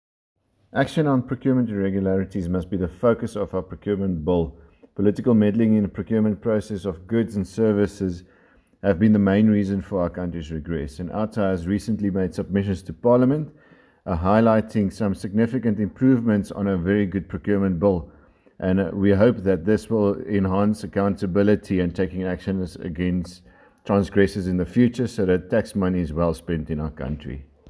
Voice Note: